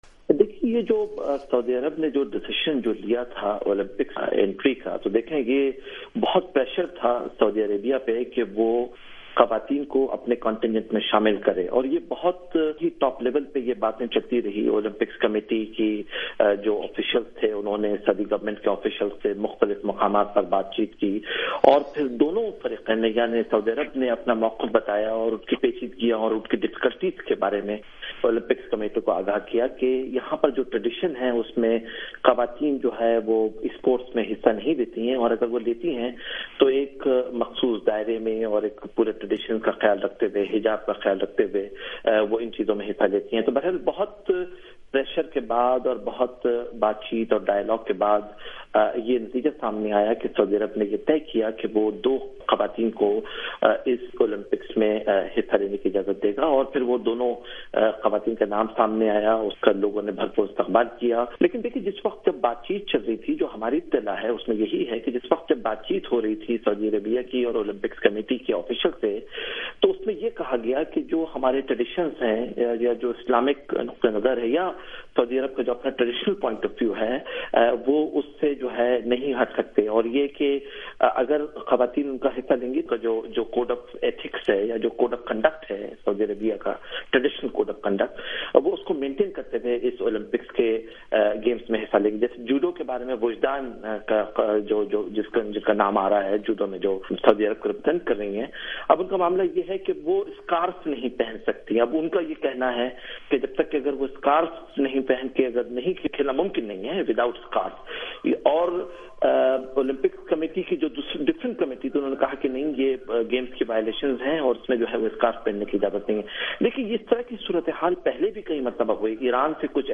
انٹرویو